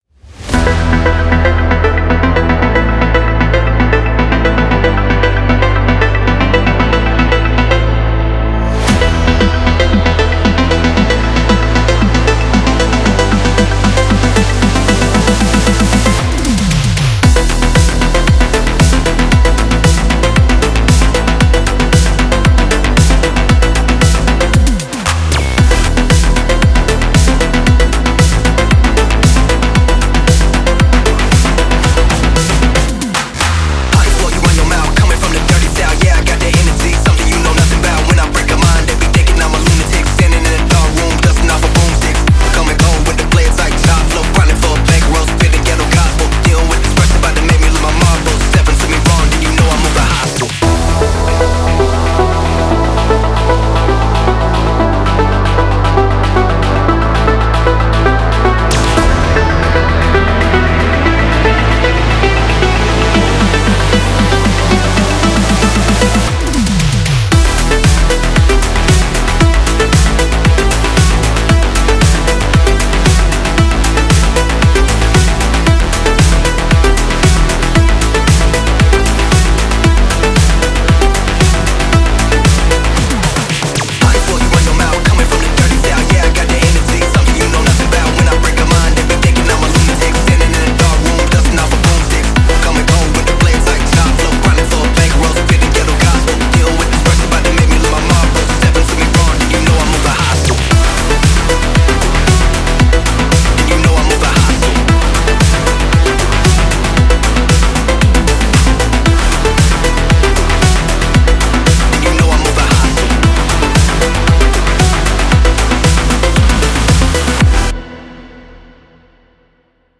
Фонк музыка
Фонк треки